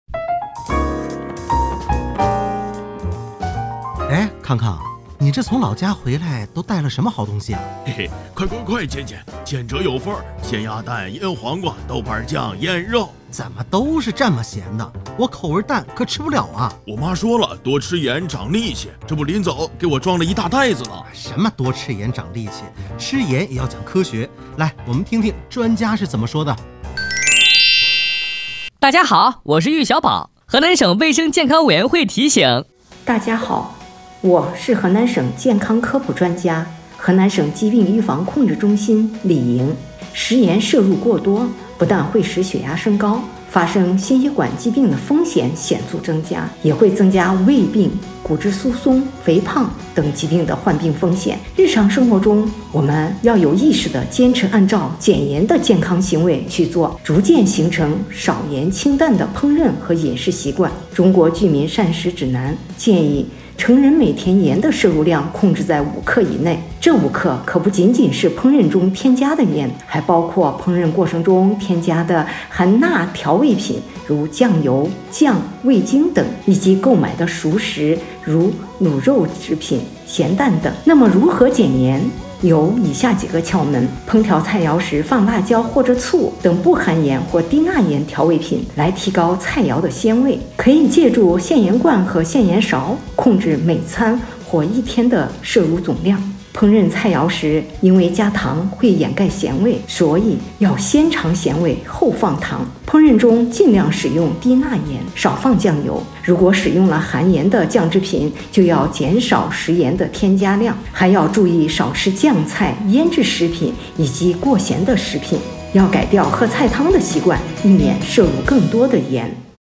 2分钟大喇叭-低盐饮食.mp3